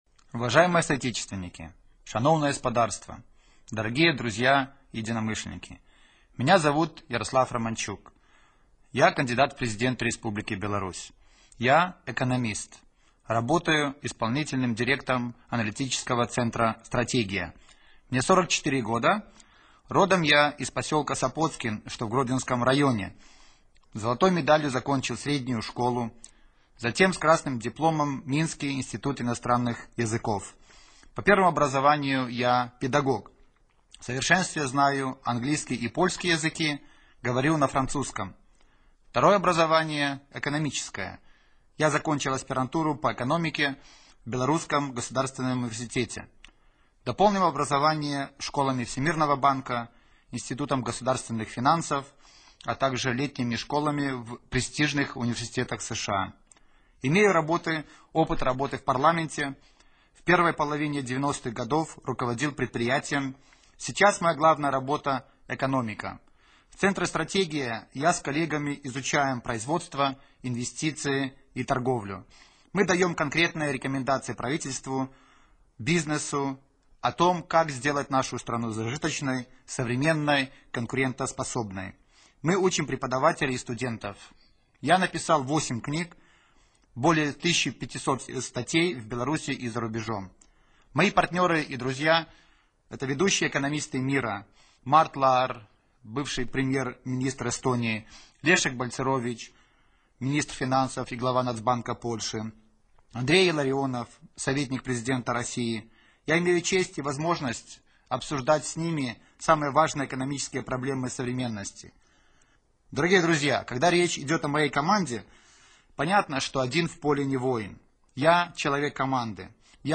Радыёвыступ Яраслава Раманчука 22.11.10